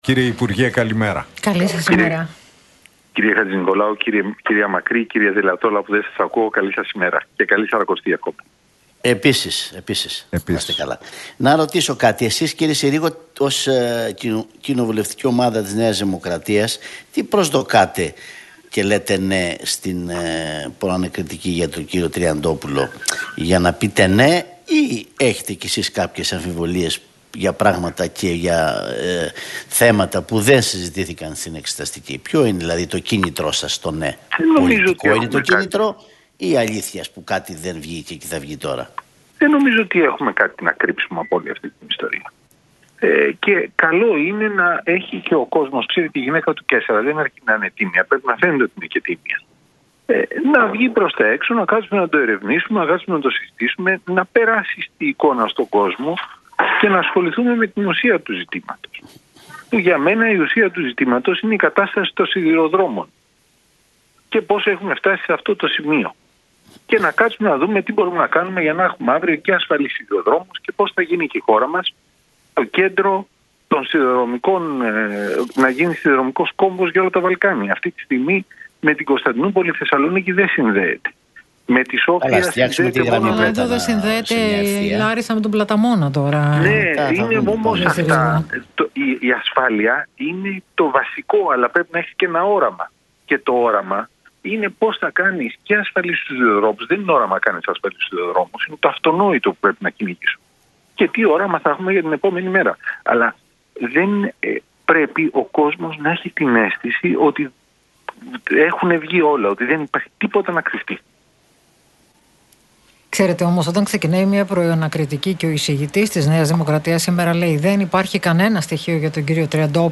Συρίγος στον Realfm 97,8: Η Αμερική θέλει να σταματήσει η αιματοχυσία στην Ουκρανία και να κινηθεί στο τρίγωνο Κίνας, Ινδίας, Ινδονησίας